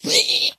zpighurt2.mp3